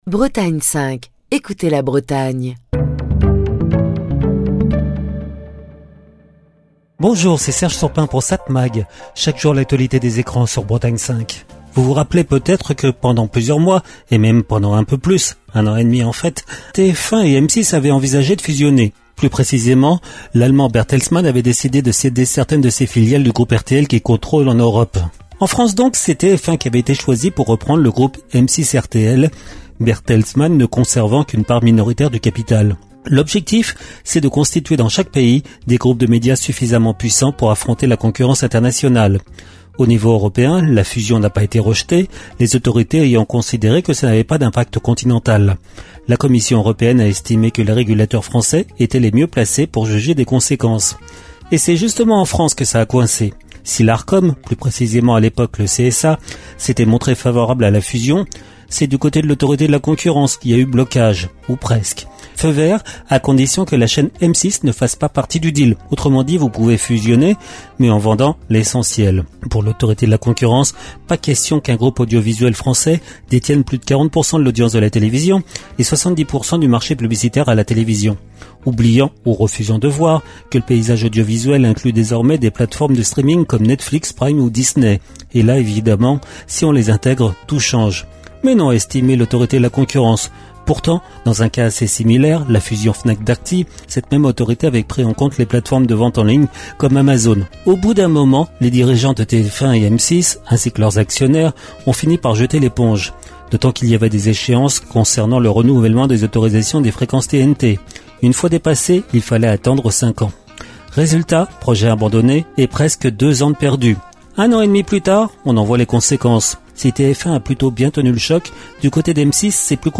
Chronique du 24 avril 2025.